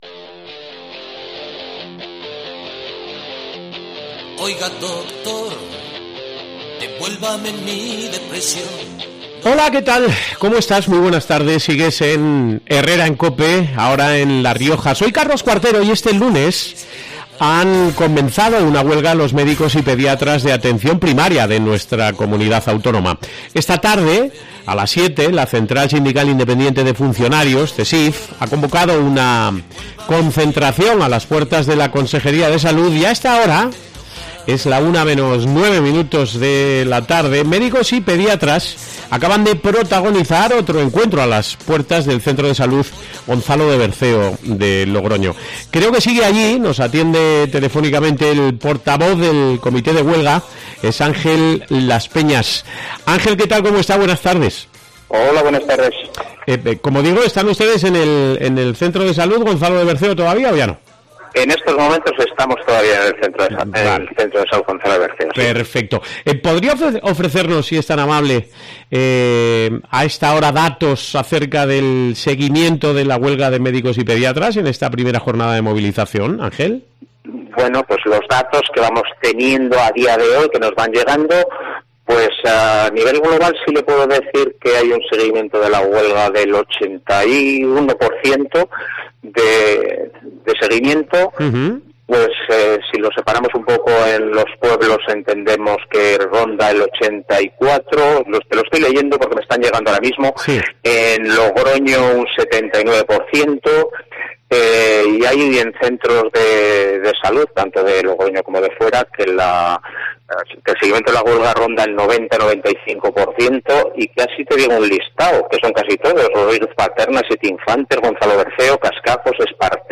Logroño